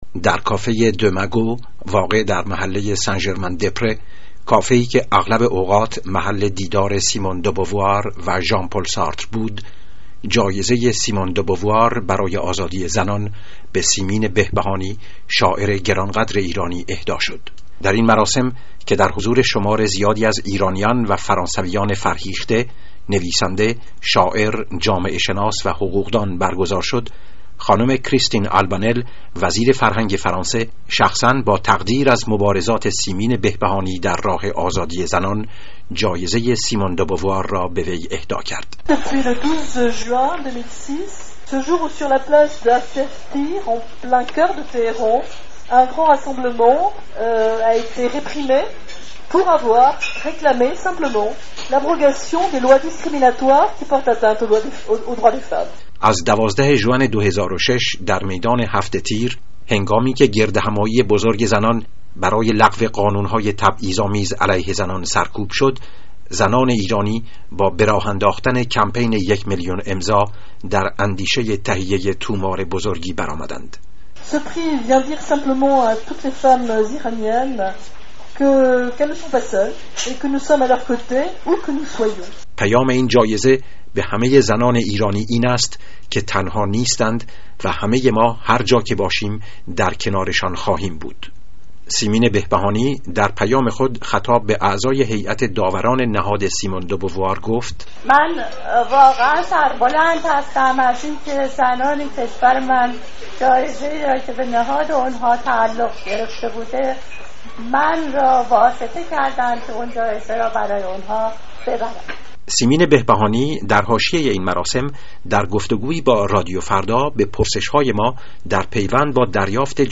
گزارش رادیویی در مورد اهدای جایزه سیمون دوبوار به سیمین بهبهانی